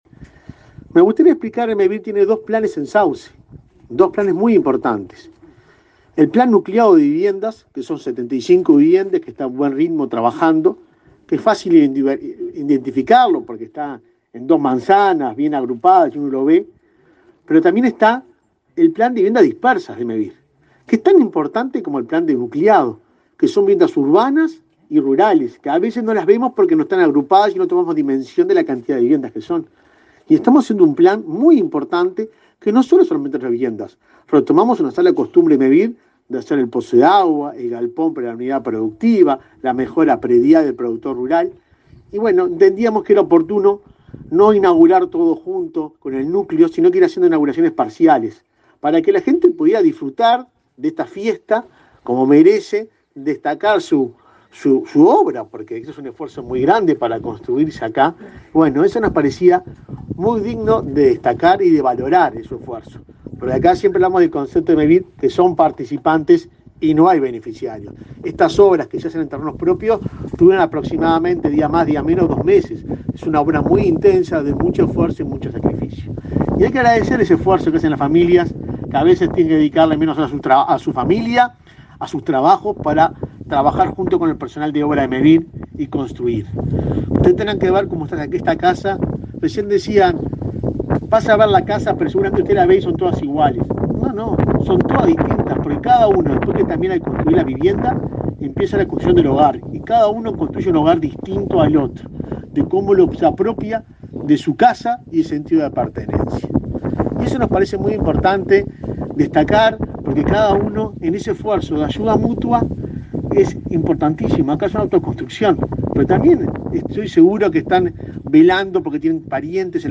Palabras de autoridades en inauguración de Mevir
El presidente de Mevir, Juan Pablo Delgado, y el subsecretario de Vivienda, Tabaré Hackenbruch, participaron en la inauguración de 10 casas en la zona